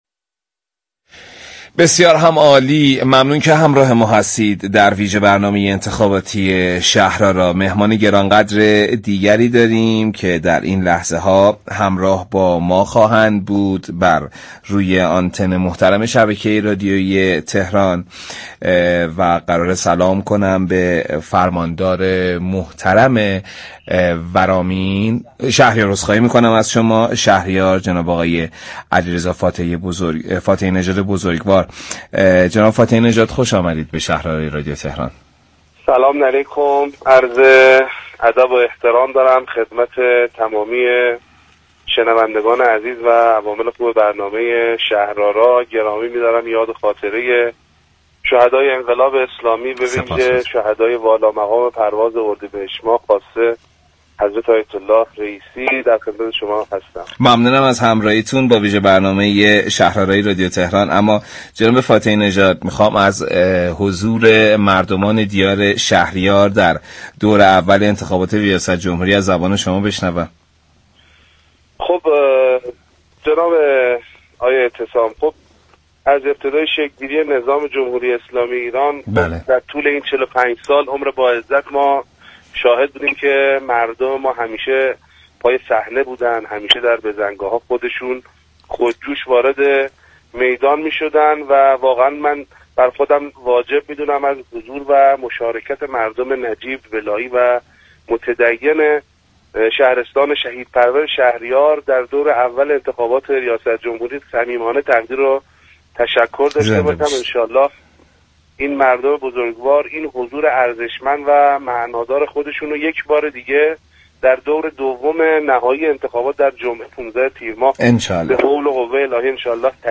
علیرضا فاتحی‌نژاد فرماندار شهرستان شهریار در گفتگو با برنامه انتخاباتی «شهر آرا»